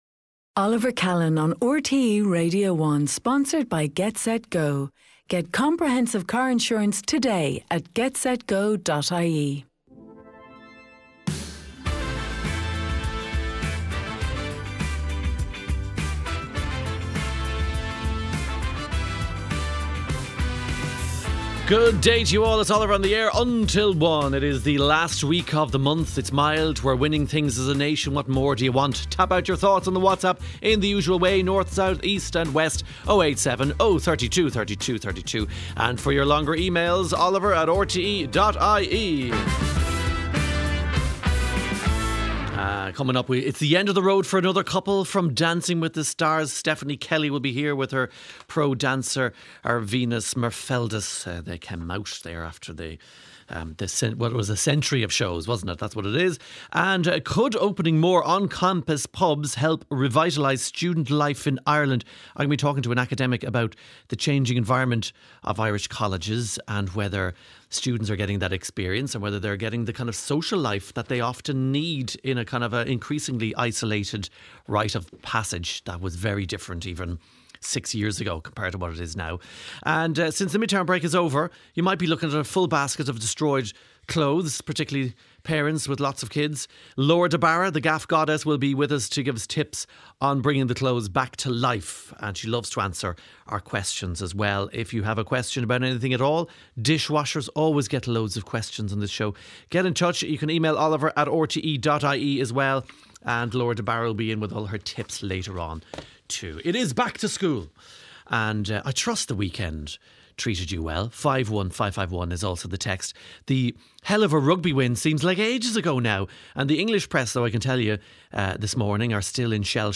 monologue